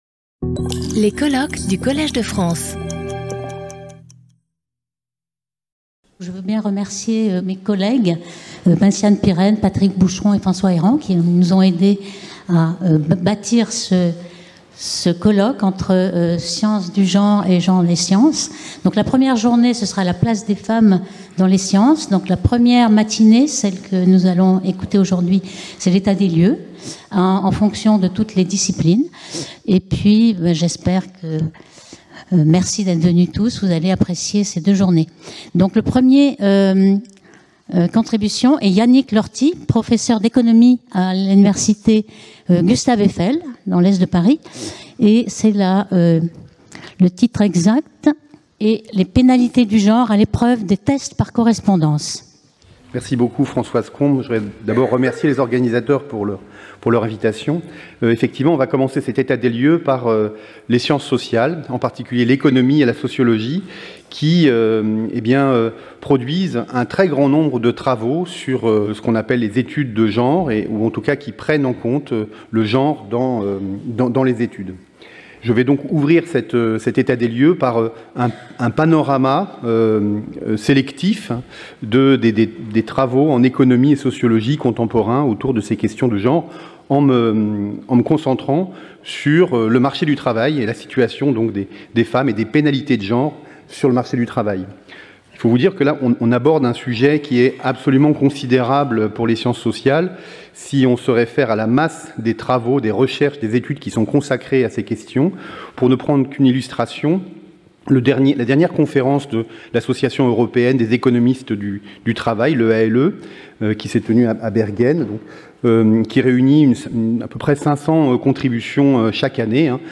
Sauter le player vidéo Youtube Écouter l'audio Télécharger l'audio Lecture audio Séance animée par Françoise Combes. Chaque communication de 30 minutes est suivie de 10 minutes de discussion.